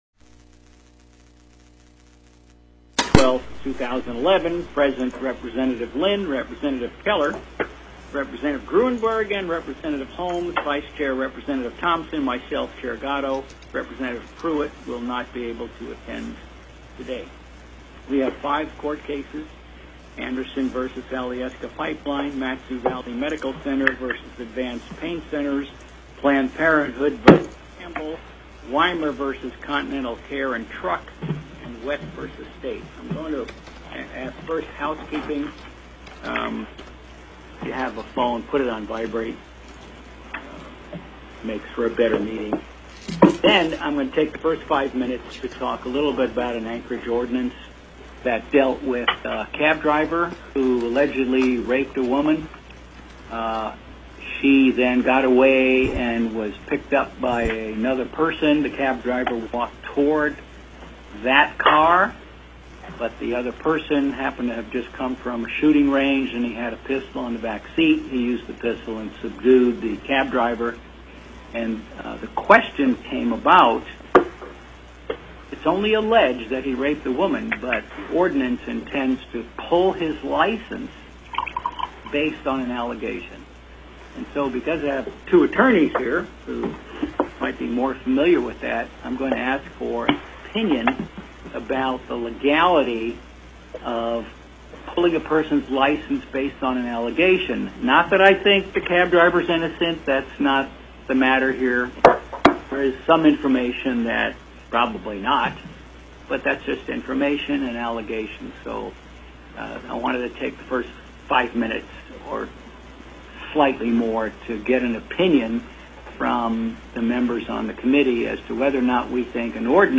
Review of select 2010 court decisions TELECONFERENCED